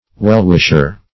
Wellwisher \Well"wish`er\, n.